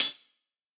UHH_ElectroHatB_Hit-32.wav